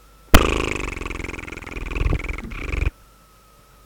The particular elephant we were on did a massive fart. Do you know what and elephant fart sounds like?
elephant_fart.wav